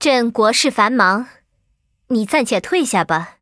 文件 文件历史 文件用途 全域文件用途 Erze_fw_02.ogg （Ogg Vorbis声音文件，长度0.0秒，0 bps，文件大小：41 KB） 源地址:游戏语音 文件历史 点击某个日期/时间查看对应时刻的文件。